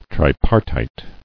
[tri·par·tite]